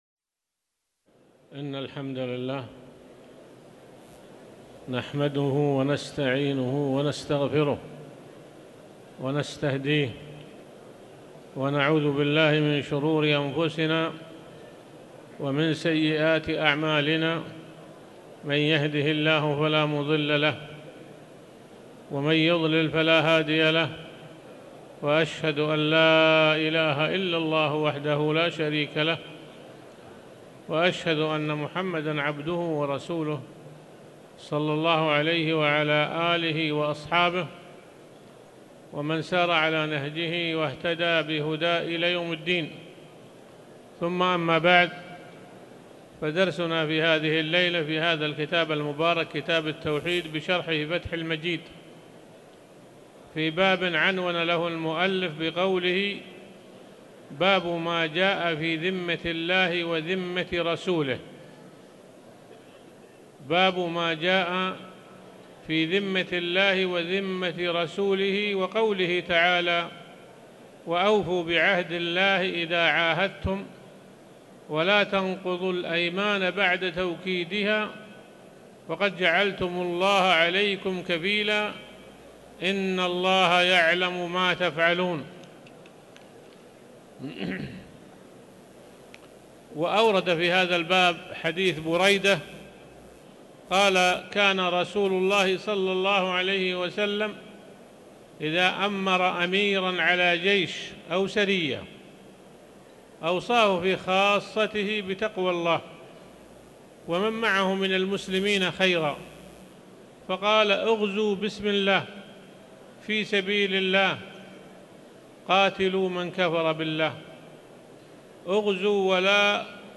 تاريخ النشر ١٦ جمادى الأولى ١٤٤٠ هـ المكان: المسجد الحرام الشيخ